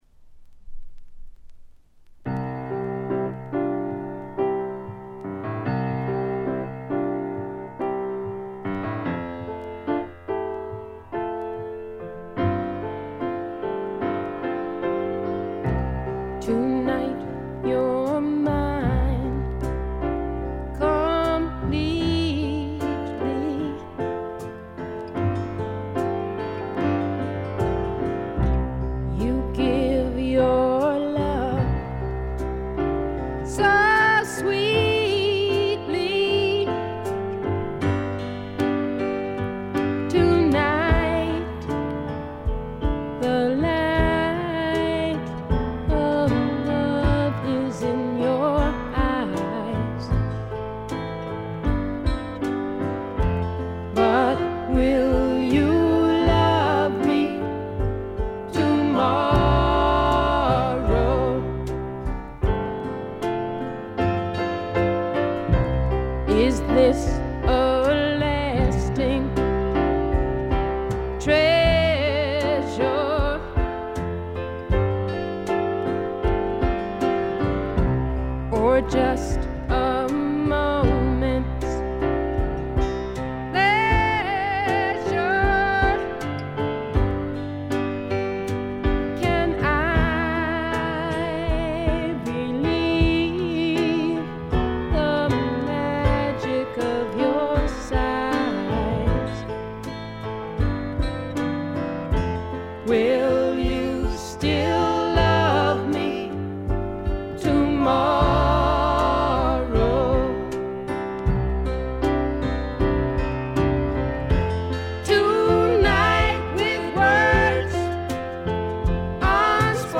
他は微細なチリプチ程度。
女性シンガーソングライター基本中の基本。
試聴曲は現品からの取り込み音源です。